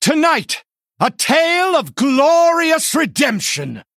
VO_KAR_114_Male_Human_Play_07.mp3